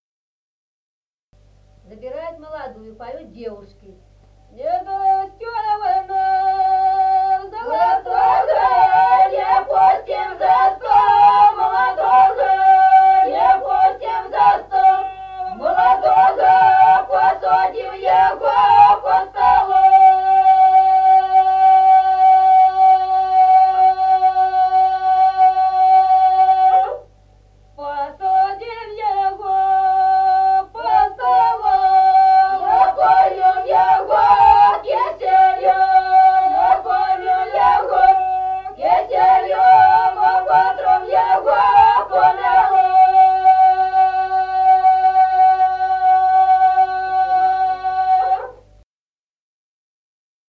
Народные песни Стародубского района «Не дасьтё вы нам», свадьба, забирают молодую, поют девушки.
запев
подголосник